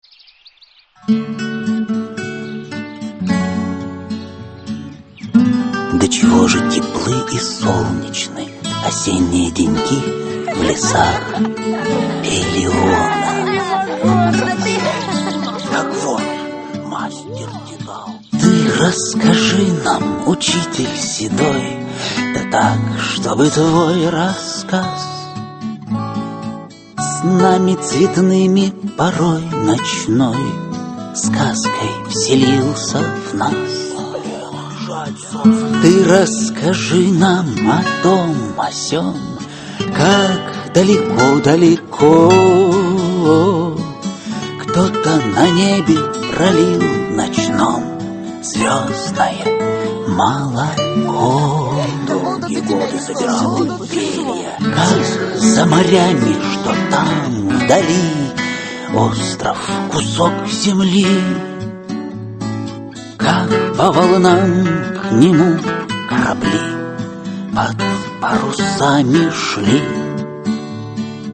Аудиокнига Легенды и мифы Древней Греции. Тесей. Аудиоспектакль | Библиотека аудиокниг